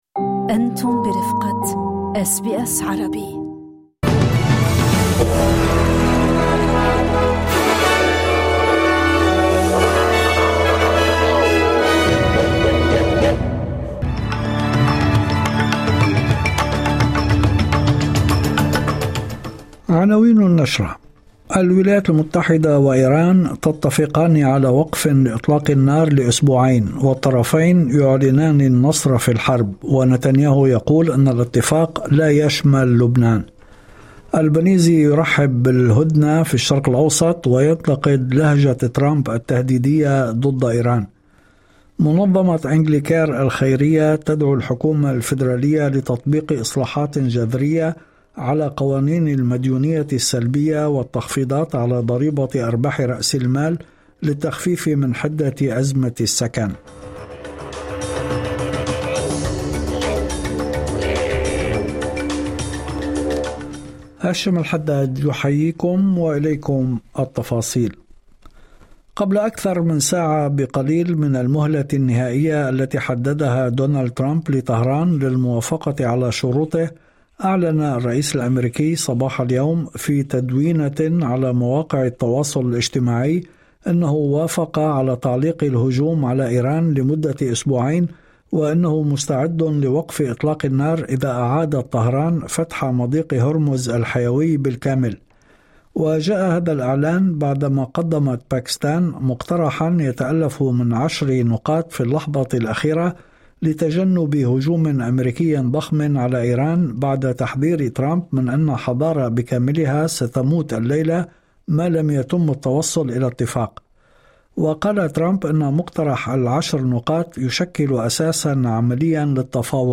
نشرة أخبار المساء 07/04/2026